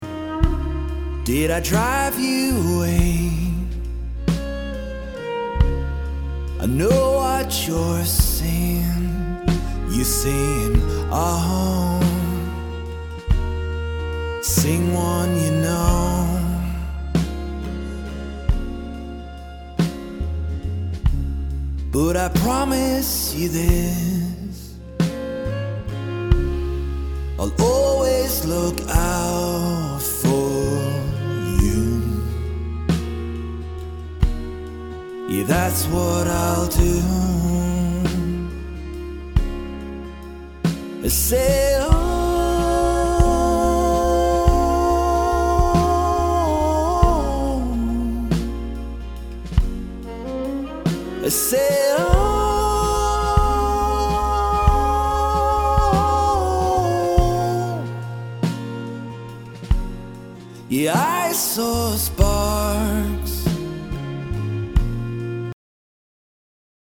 thrilling upbeat folk outfit
folk pop outfit
fiddle